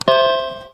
button.wav